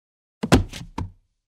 Звуки падений человека
Здесь вы найдете как комичные, так и резкие, реалистичные варианты.
Звук приземления человека на деревянный пол после прыжка